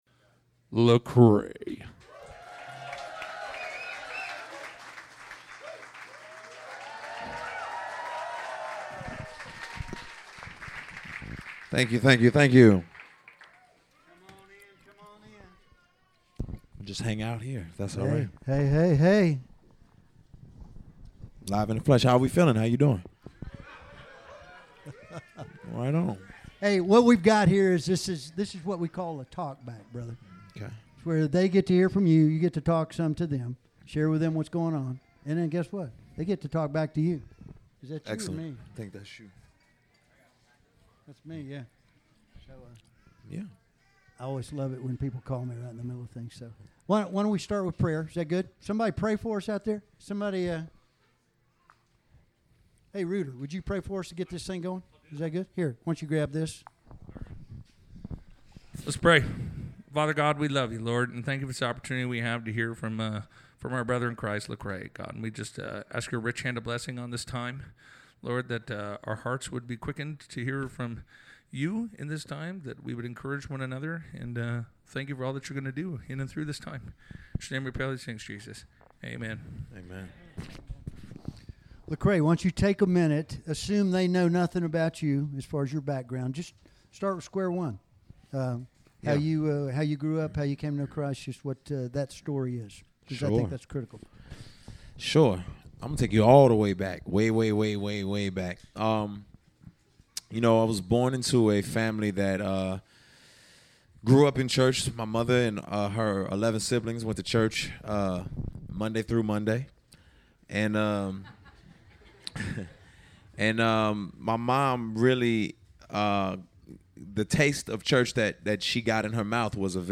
SPECIAL FREE TALKBACK: Listen to our 30 minute artist talkback conversation with Lecrae at the Creation Northeast Festival.
6-24-15-Lecrae_talkback.mp3